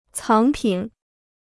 藏品 (cáng pǐn): museum piece; collector's item.